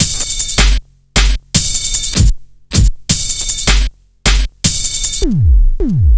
Index of /90_sSampleCDs/Zero-G - Total Drum Bass/Drumloops - 1/track 03 (155bpm)